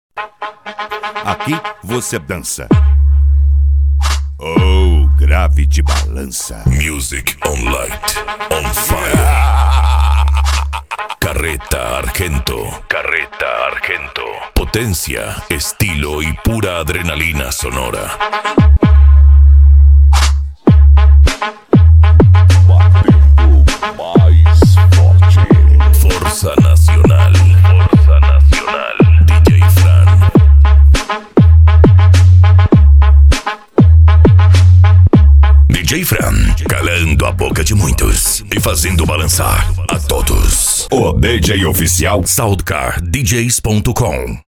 Bass
Psy Trance
Racha De Som
Remix